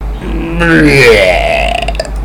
Audio / SE / Cries / STUNFISK.mp3
STUNFISK.mp3